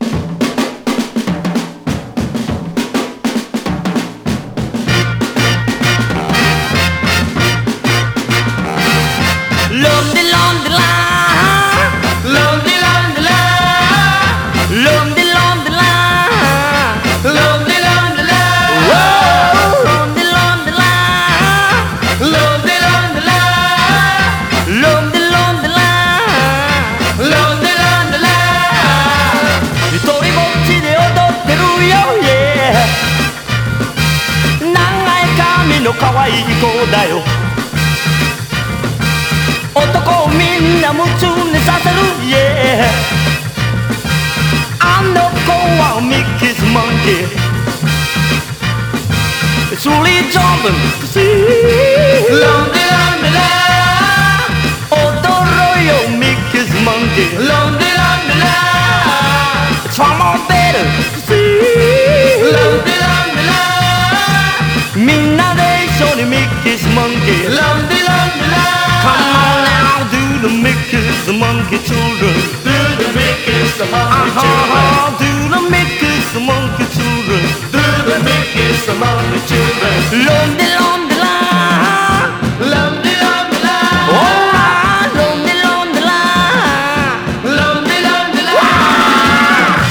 SPACE / COSMIC / FUSION / SYNTHESIZER
和製コズミック・グルーヴ/フュージョン！